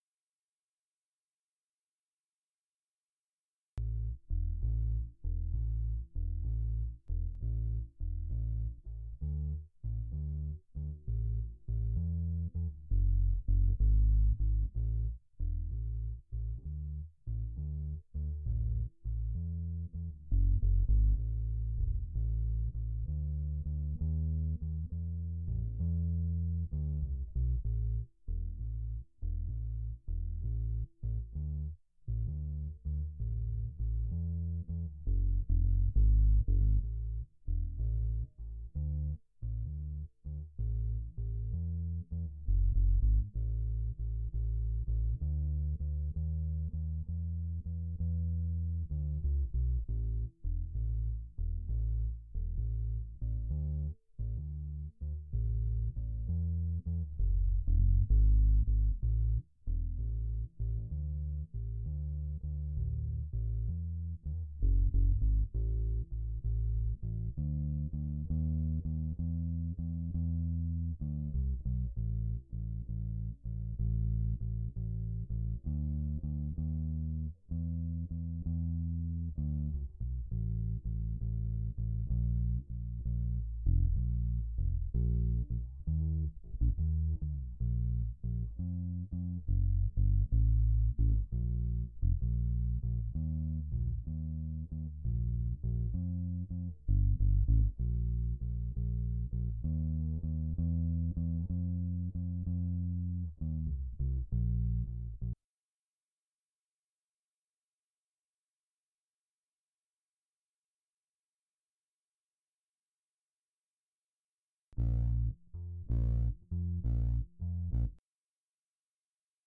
BASSE.mp3